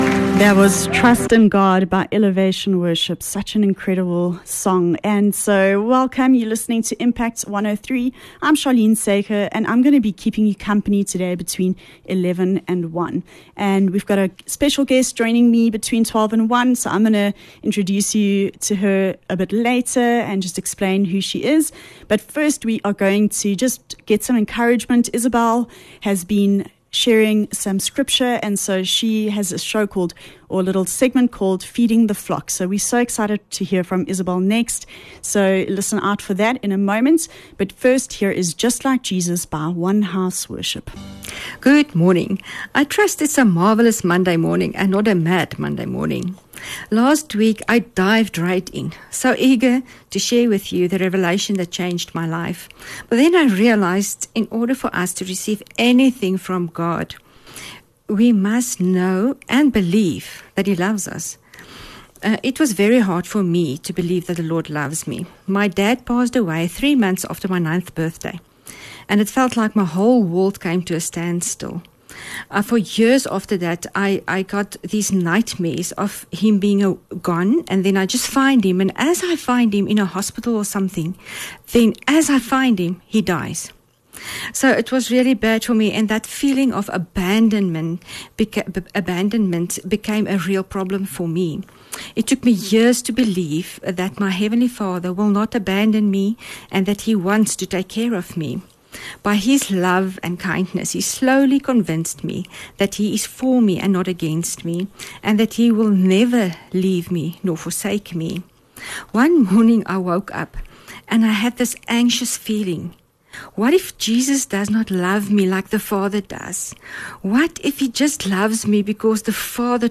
13 Mar Messy Motherhood - Interview